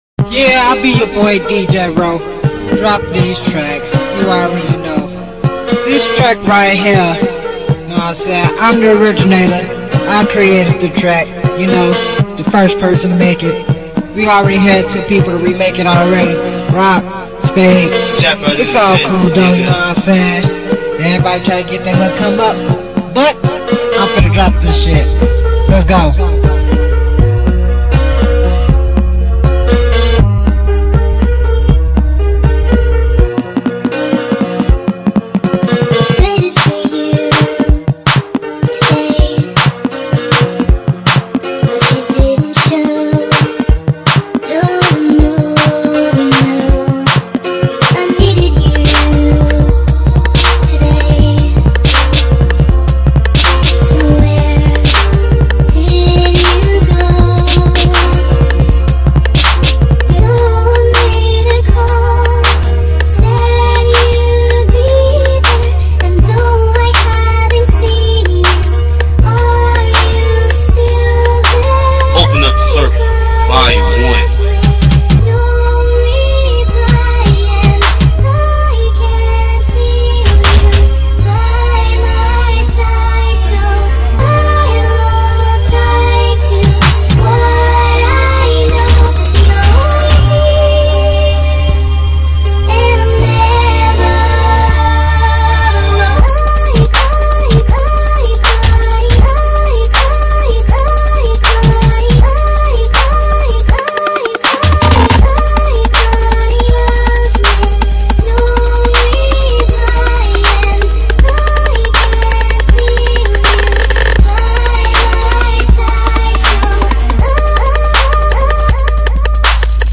footwork